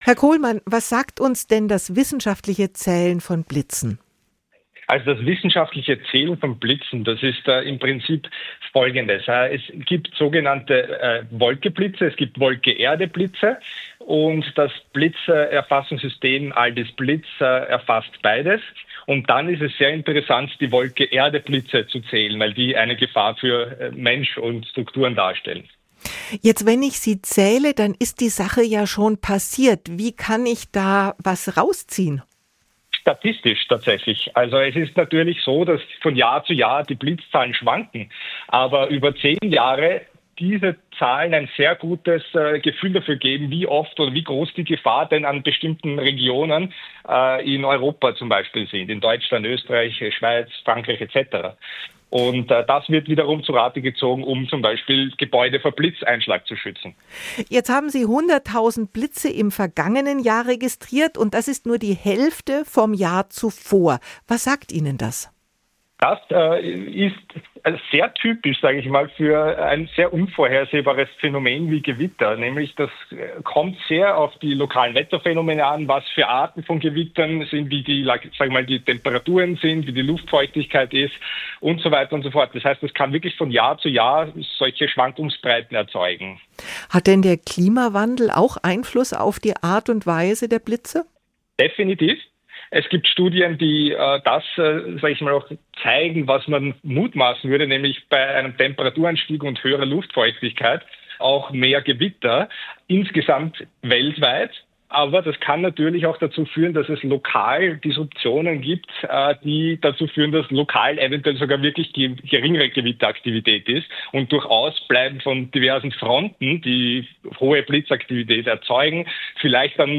Die besten Interviews aus dem Radioprogramm SWR Aktuell: jederzeit zum Nachhören und als Podcast im Abo